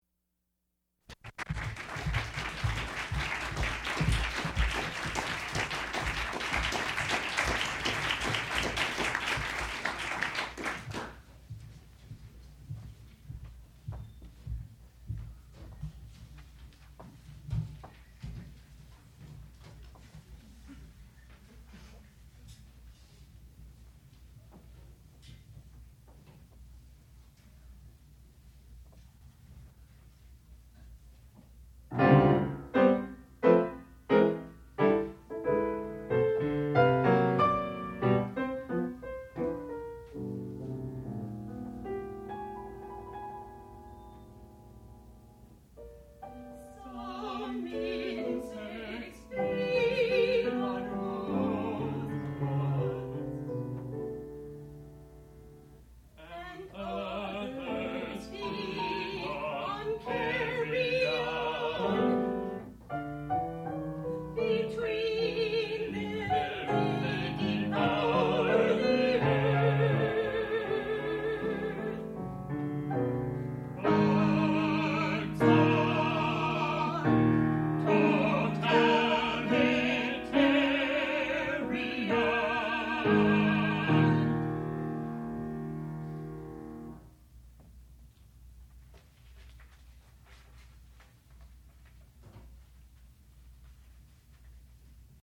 sound recording-musical
classical music
soprano
piano
baritone